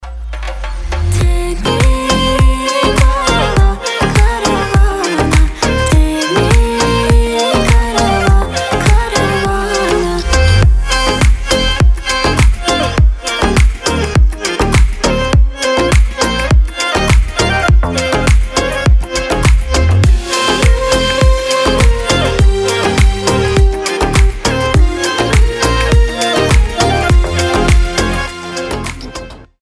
• Качество: 320, Stereo
заводные
Dance Pop
восточные